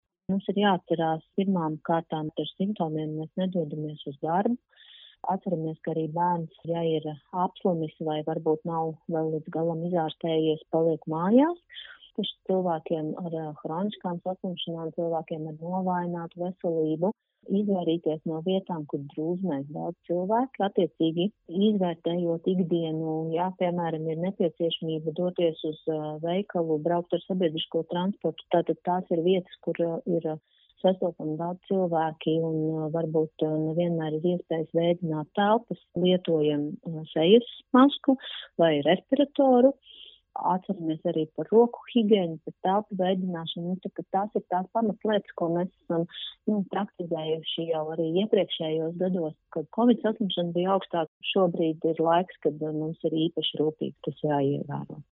RADIO SKONTO Ziņās atgādinājums par gripas profilaksi